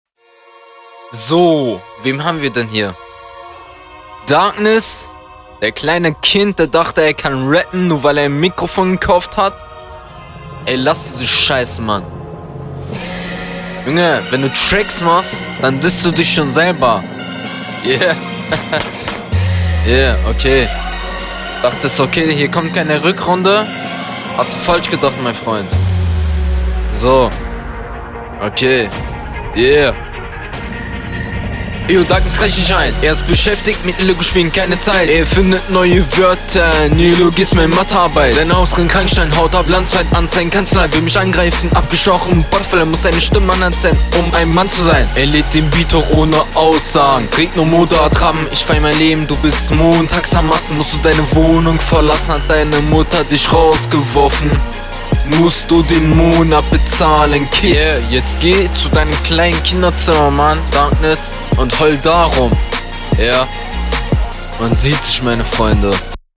Steigst hier aber sehr offpoint …